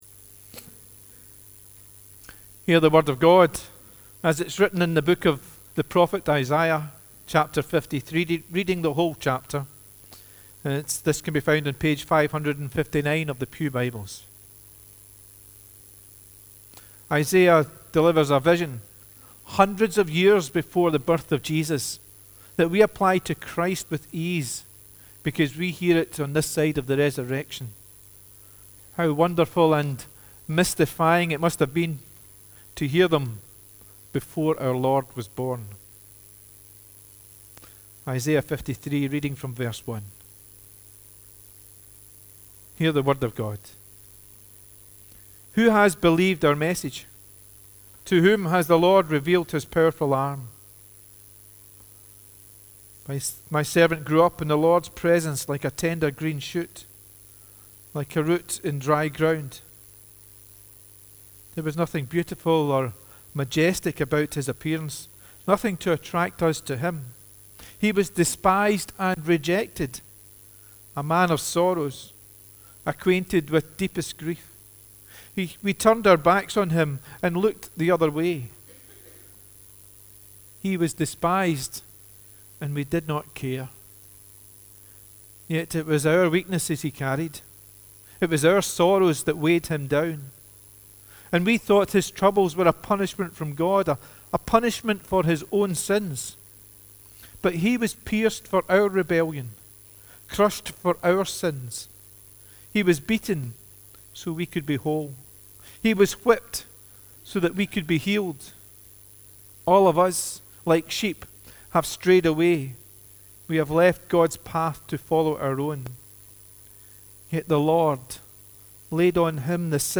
The Scripture Readings prior to the Sermon are Isaiah 53: 1-12 and Acts 8: 26-40